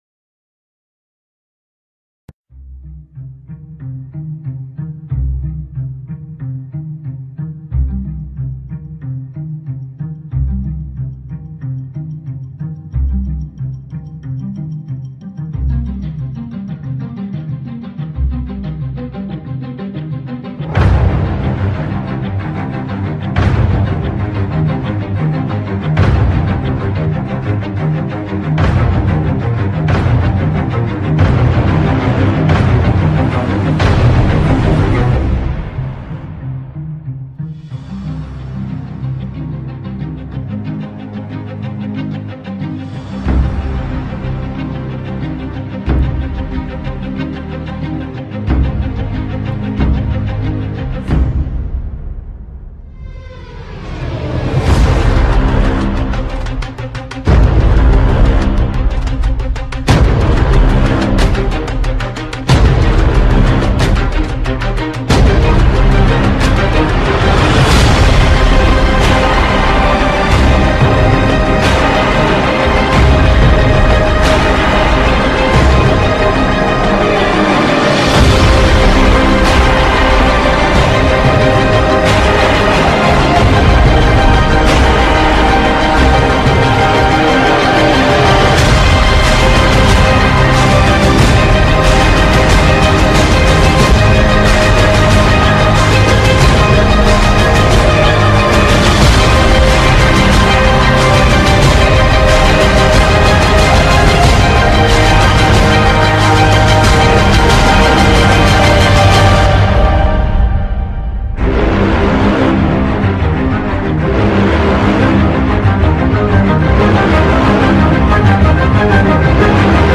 MUSICA-DE-MISTERIO-Efecto-de-Sonido.mp3
LBep4QWofPs_MUSICA-DE-MISTERIO-Efecto-de-Sonido.mp3